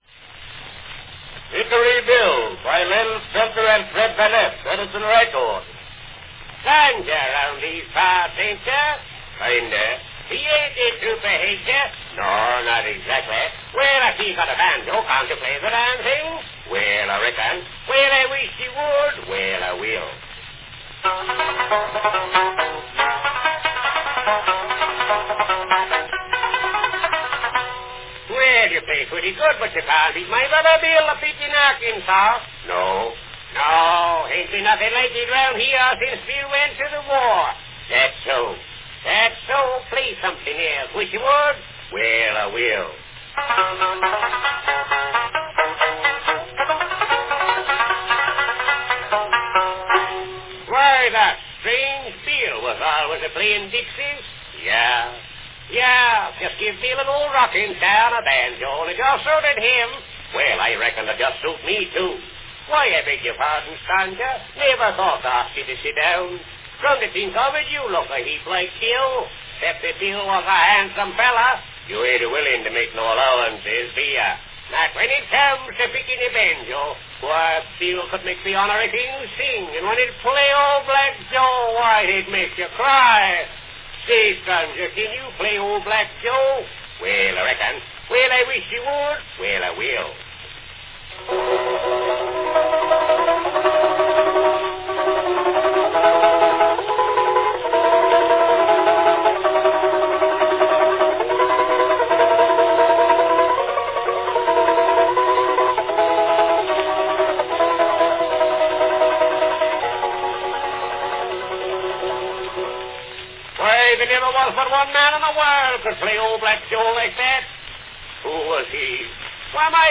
Category Talking & banjo